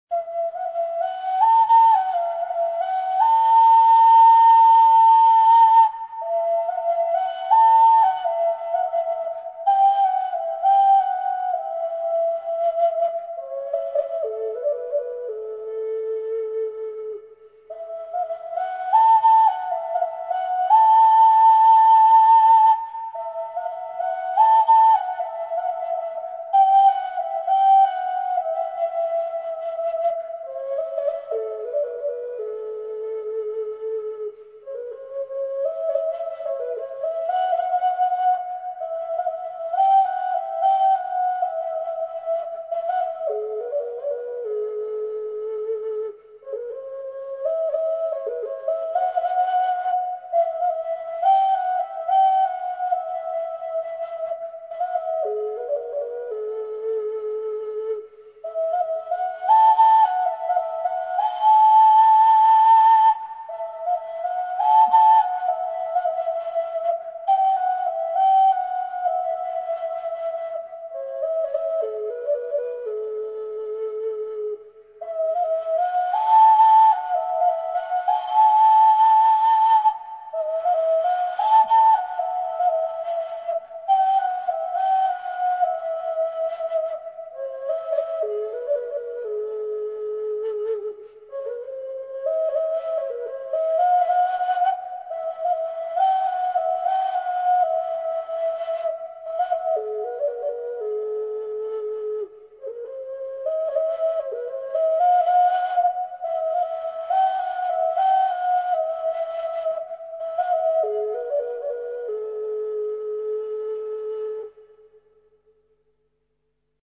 широко известная народная песня.
Переложение для саз-сырная.
Саз-сырнай